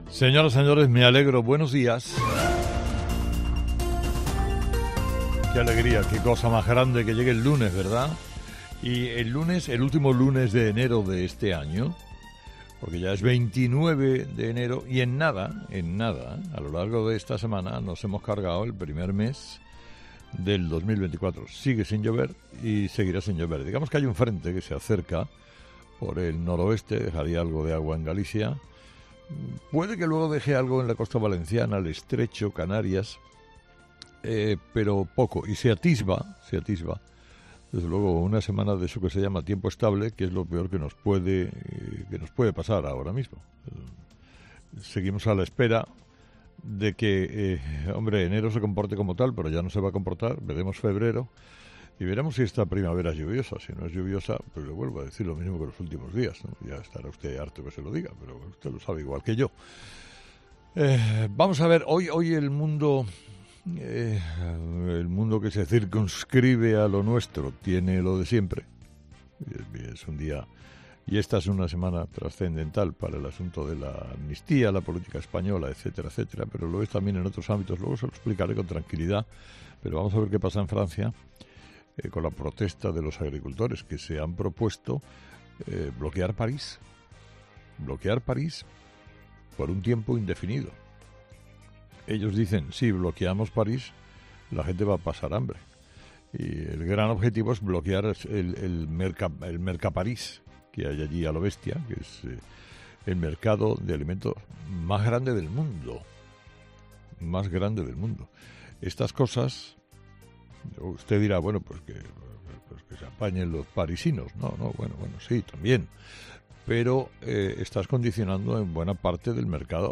AUDIO: Escucha el análisis de Carlos Herrera a las 06:00 en Herrera en COPE del lunes 29 de enero